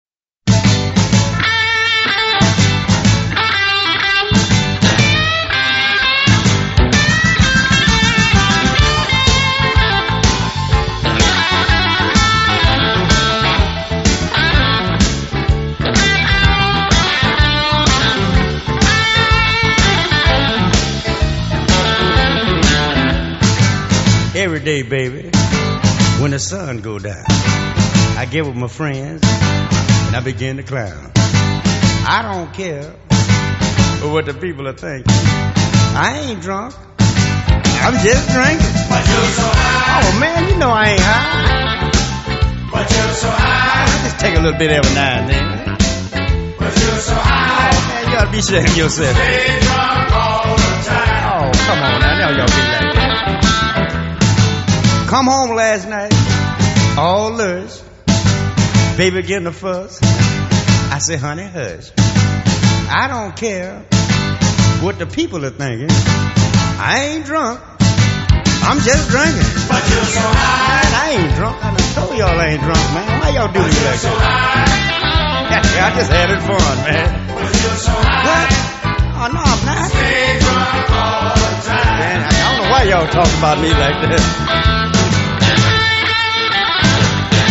藍調音樂